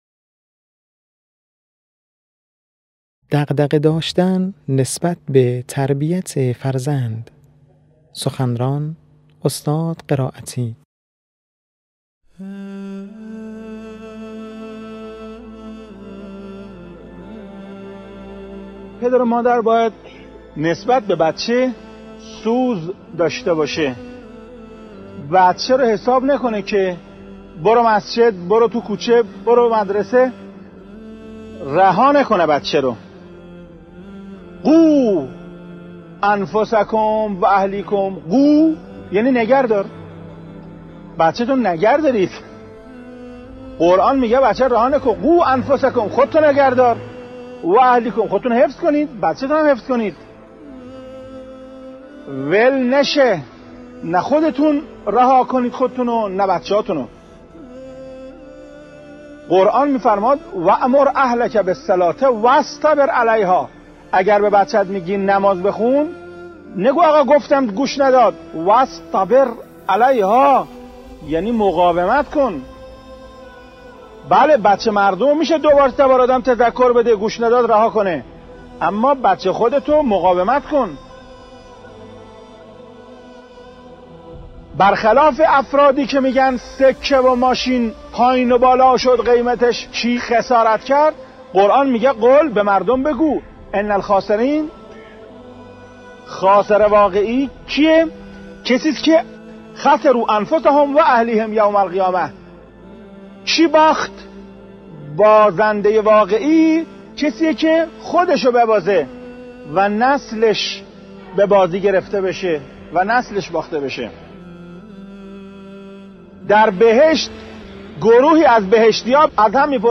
حجت‌الاسلام والمسلمین محسن قرائتی، رئیس ستاد اقامه نماز در یکی از سخنرانی‌های خود با تأکید بر لزوم دغدغه داشتن نسبت به تربیت فرزند آیاتی از قرآن کریم را یادآور شد و گفت: قرآن می‌فرماید بازنده واقعی کسی است که خودش را ببازد و نسلش به بازی گرفته شود.